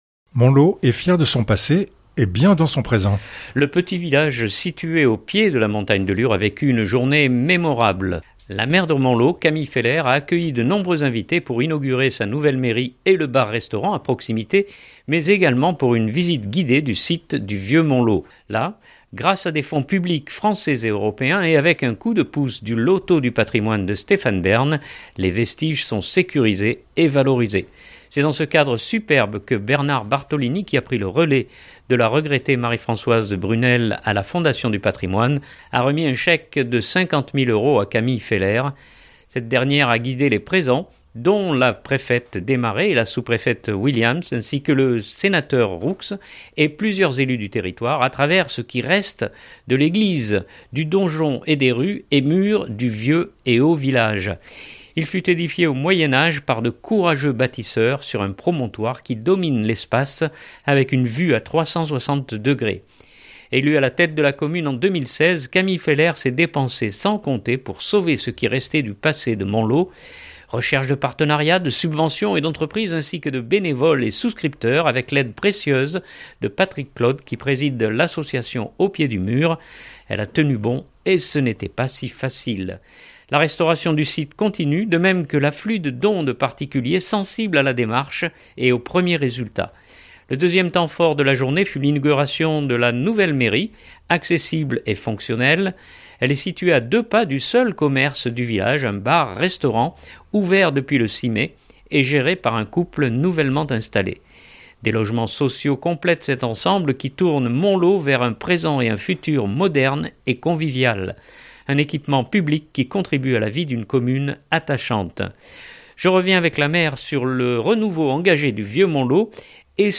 Je reviens avec la maire sur le renouveau engagé du Vieux Montlaux et sur les nouveaux services dans son village. Puis vous entendrez la préfète Démaret évoquer le soutien de l’Etat via notamment l’agenda rural en direction des petites communes.